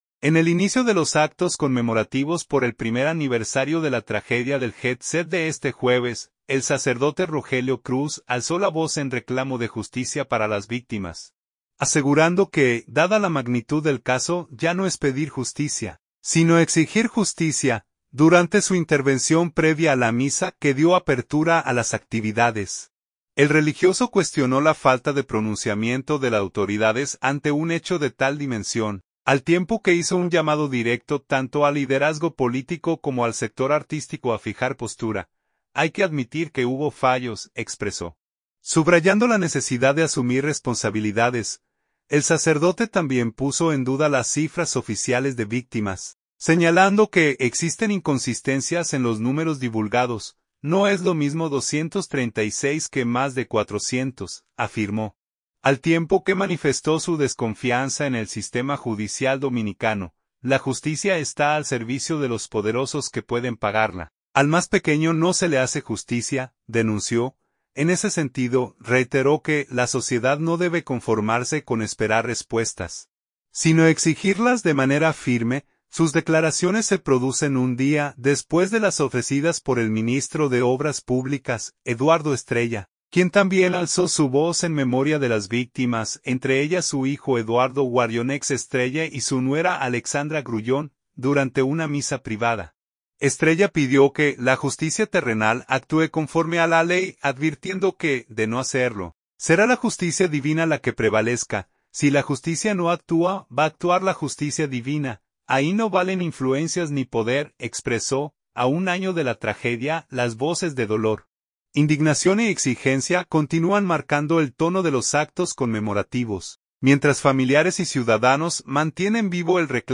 Durante su intervención previa a la misa que dio apertura a las actividades, el religioso cuestionó la falta de pronunciamiento de las autoridades ante un hecho de tal dimensión, al tiempo que hizo un llamado directo tanto al liderazgo político como al sector artístico a fijar postura.